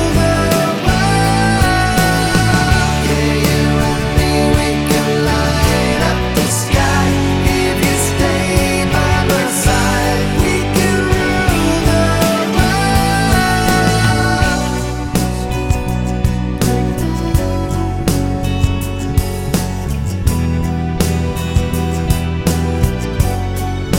No Piano With Backing Vocals Pop (1990s) 4:03 Buy £1.50